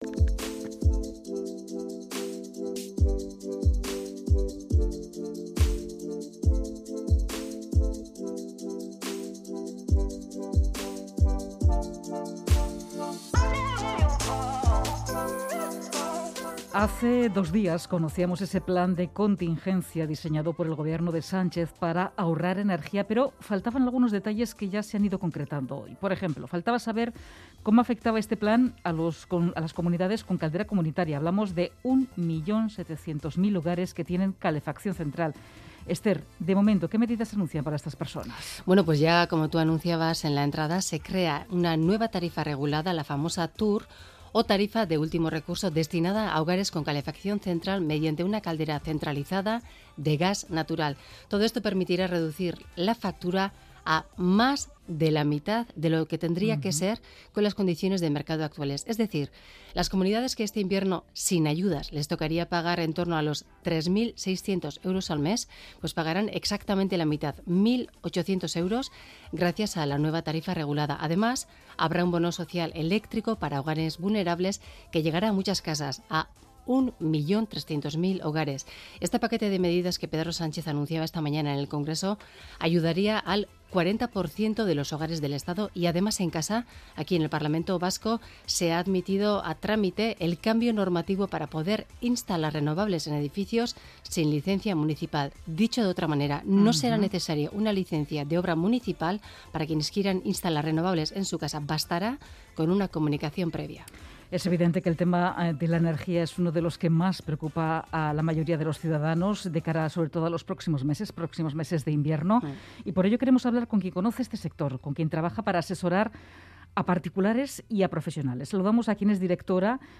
Se lo preguntamos a tres profesionales del sector energético e inmobiliario